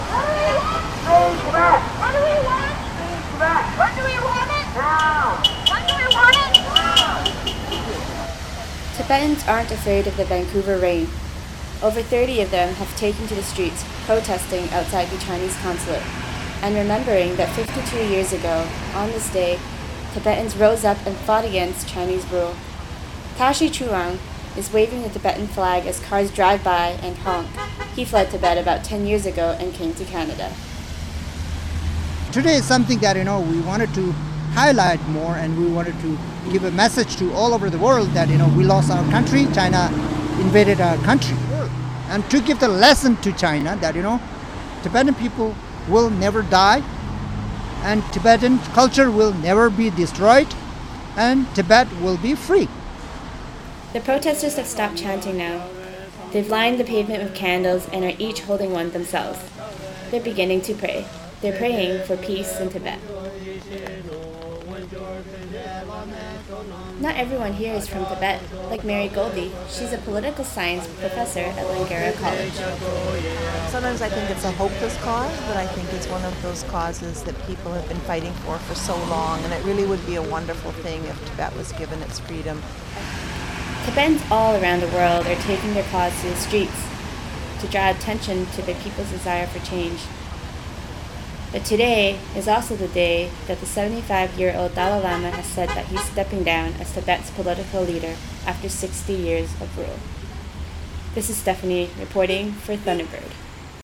A rally outside the Chinese Consulate in Vancouver
On March 10, 2011, a group of Tibetans and their supporters staged a rally and candlelight vigil outside the Chinese Consulate in Vancouver, on Granville street.